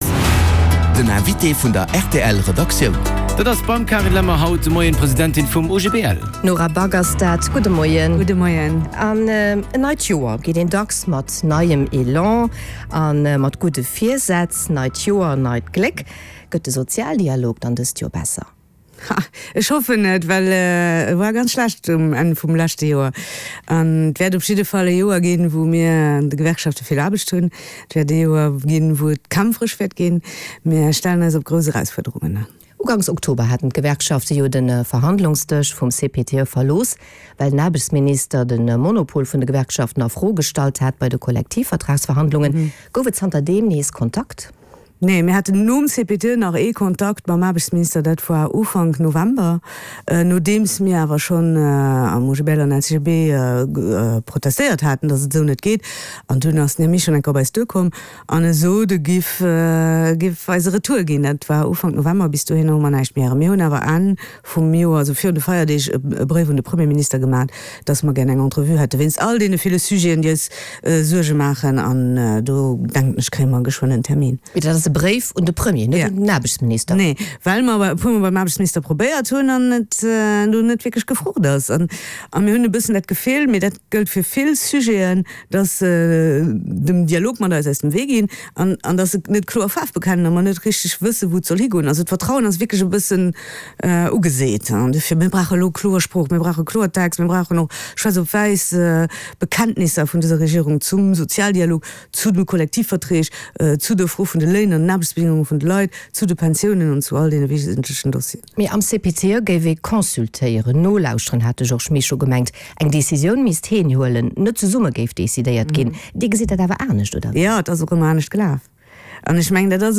Den Hannergrondinterview mat Vertrieder aus Politik an Zivilgesellschaft